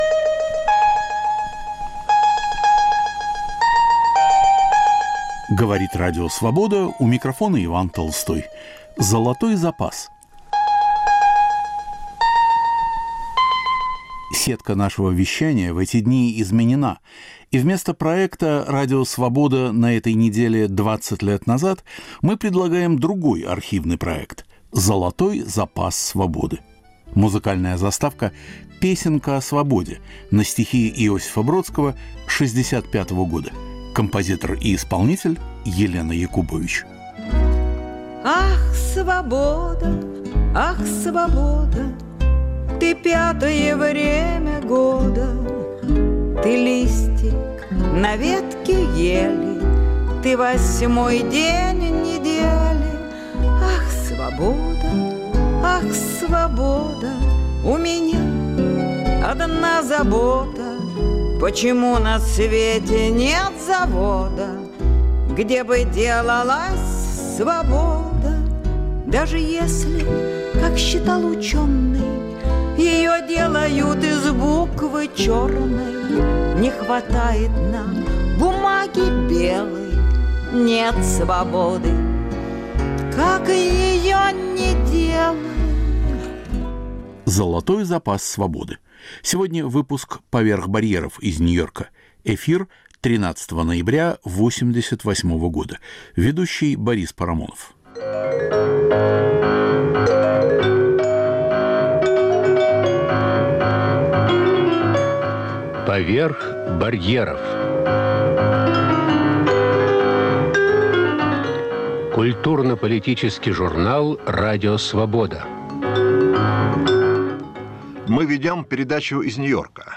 Ведущий Борис Парамонов. Биография и судьба: Сергей Довлатов о новой книге о Марине Цветаевой. Обратная сторона журнального бума в СССР. Правда о Горьком и неправда о Ленине.